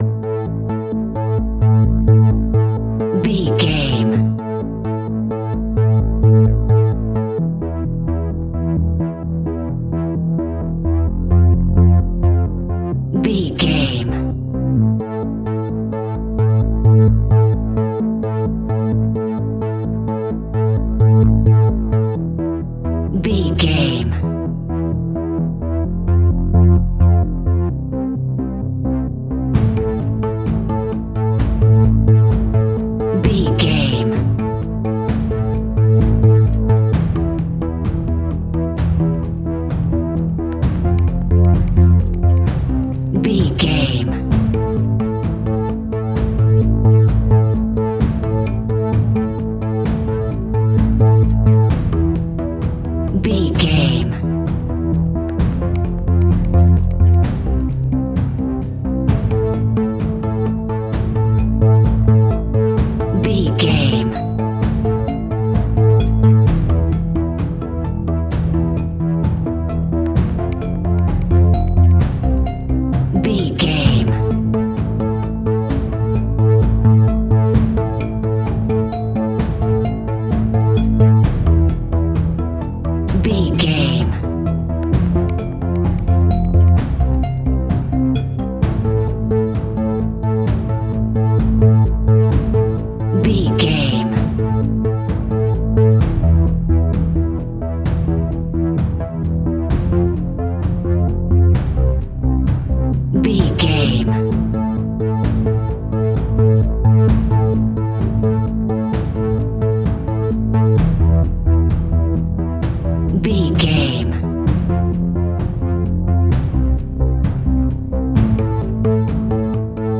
Aeolian/Minor
piano
synthesiser